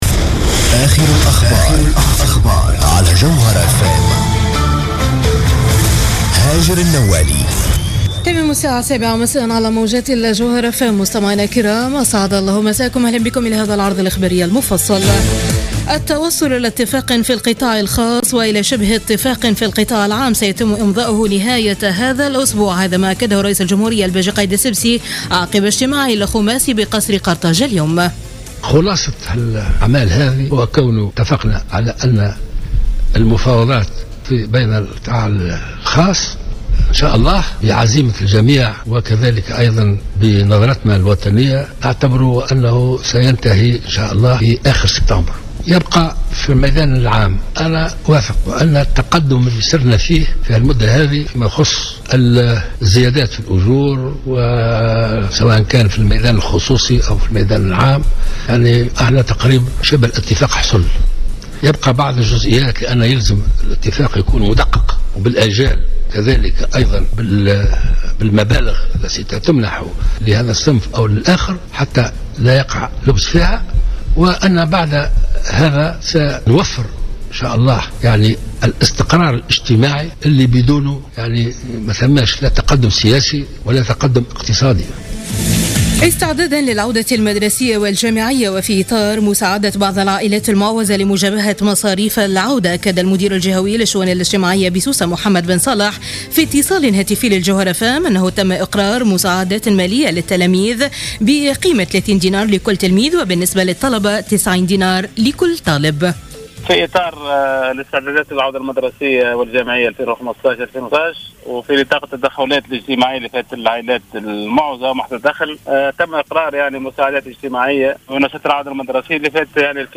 نشرة أخبار السابعة مساء ليوم الاثنين 7 سبتمبر 2015